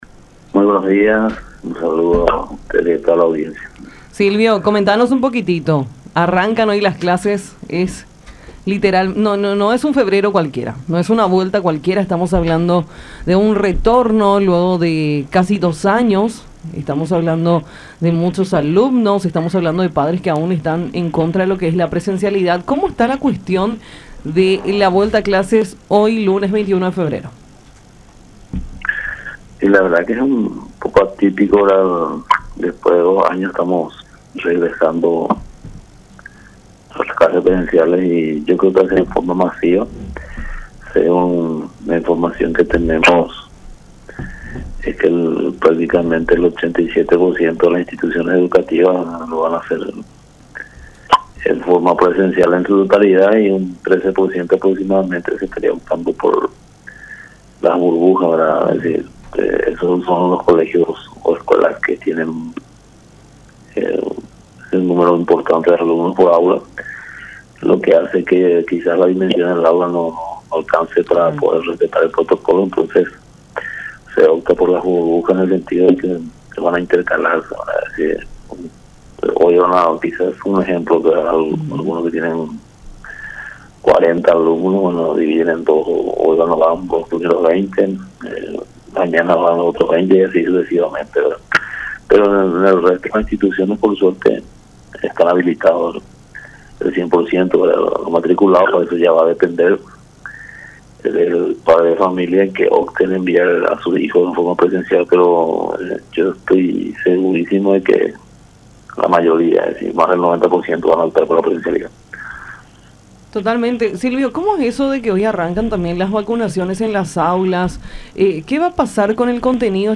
en diálogo con Nuestra Mañana a través de La Unión.